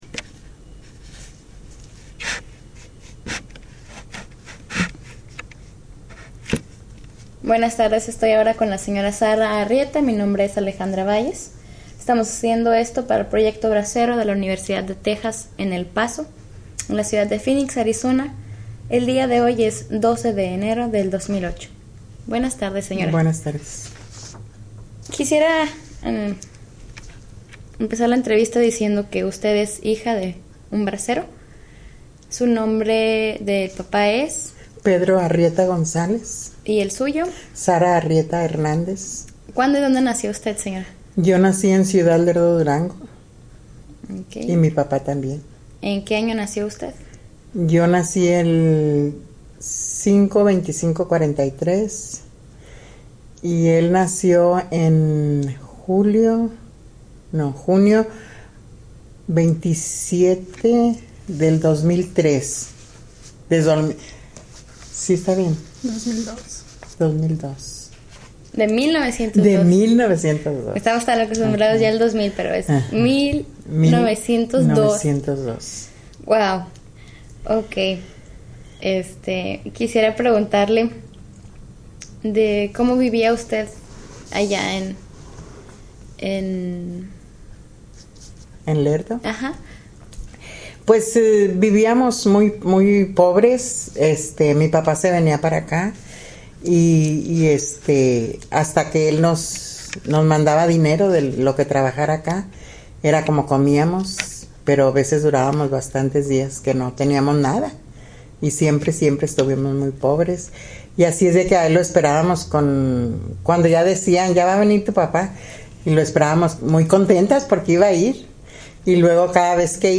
Summary of Interview:
Location Phoenix, Arizona